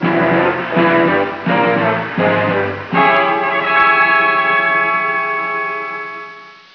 Dramatic Music Cue - 73k